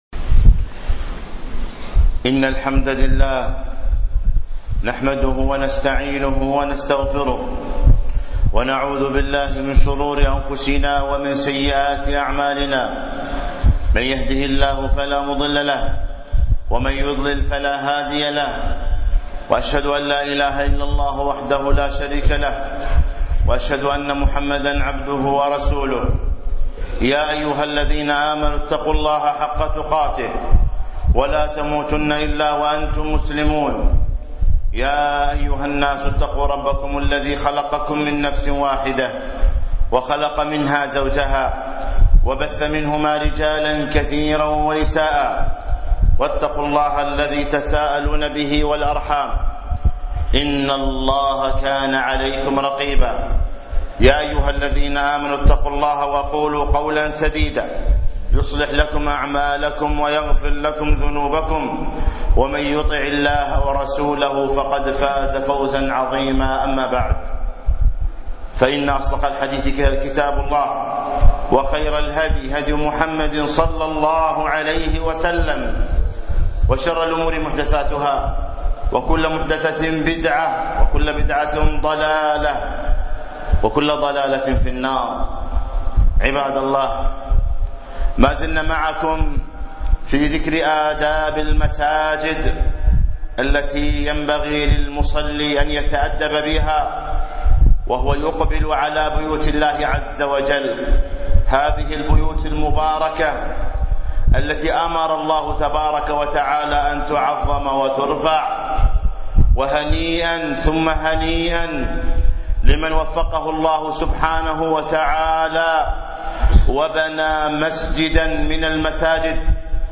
آداب المسجد الخطبة الثانية